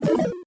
Cancel.wav